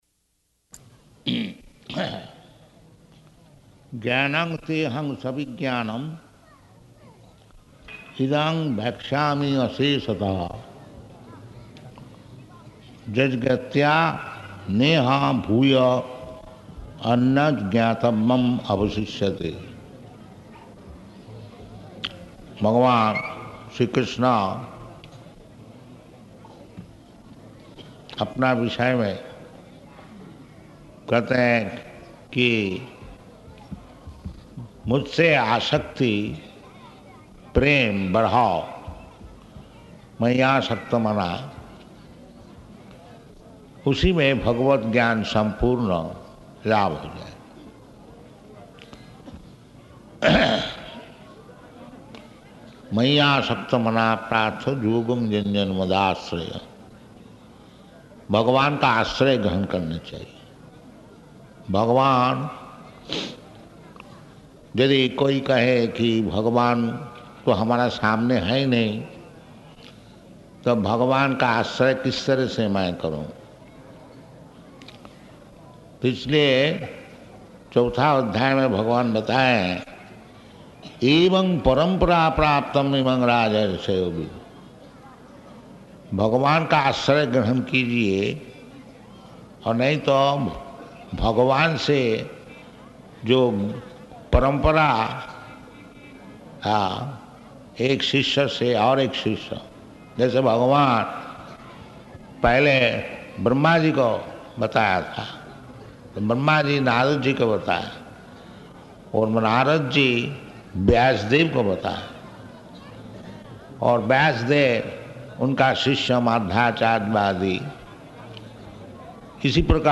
Location: Sanand